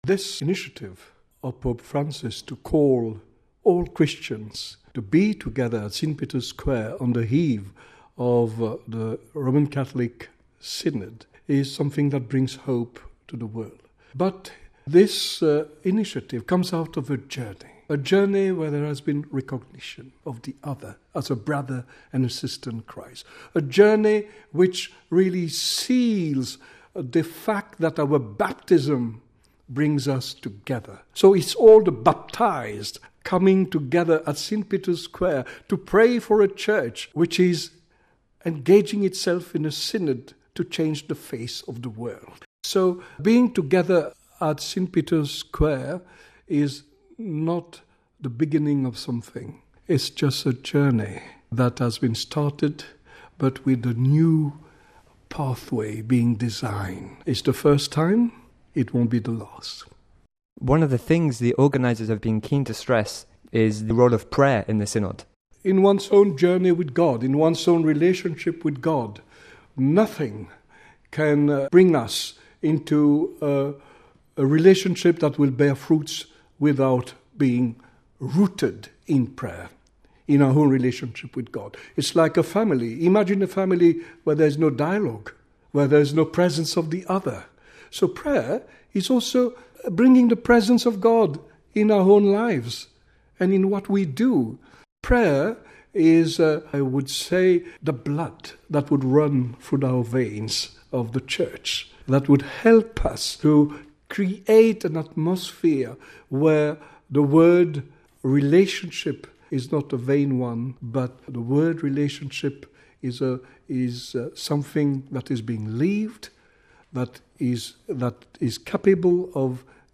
That’s the bold claim made by Archbishop Ian Ernest, Personal Representative of the Archbishop of Canterbury to the Holy See, in an interview with Vatican News.